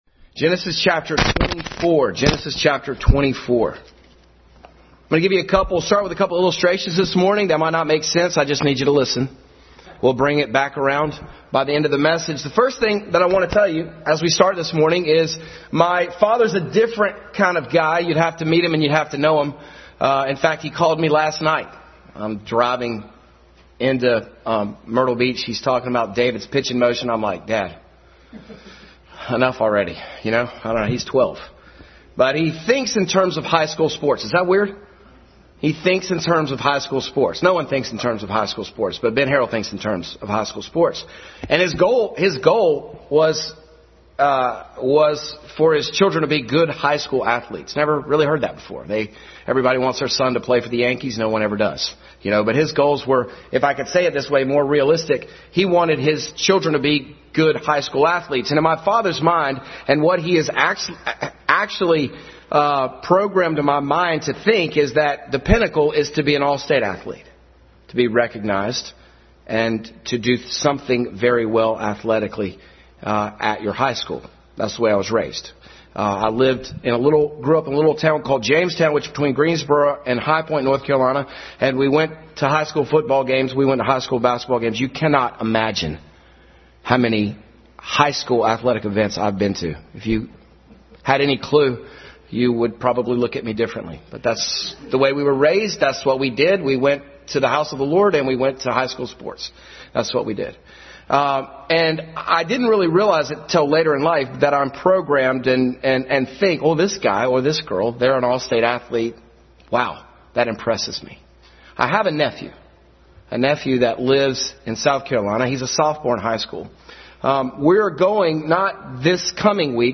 Genesis 24 Passage: Genesis 24:2-4, John 15:26, 14:16 Service Type: Family Bible Hour Family Bible Hour message.